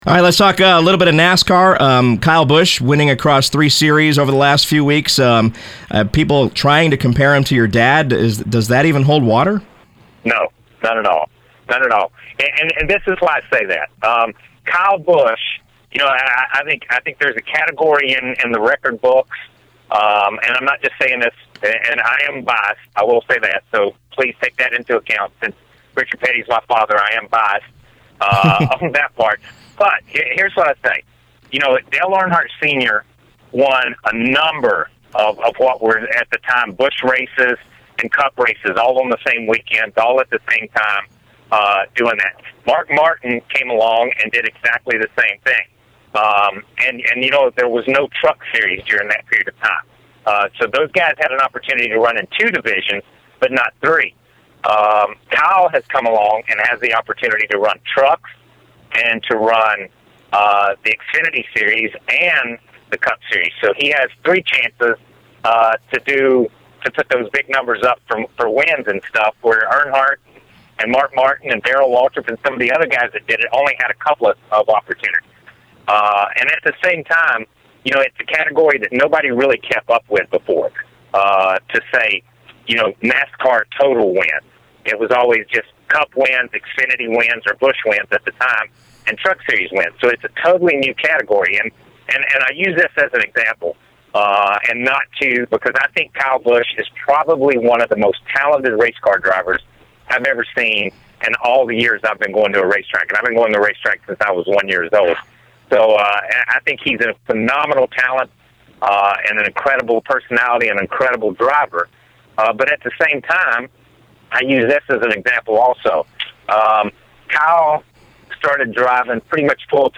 During an interview with The Final Lap, it became apparent that one person who doesn’t understand the comparison is Petty’s son, and former eight-time Sprint Cup race winning driver, Kyle Petty.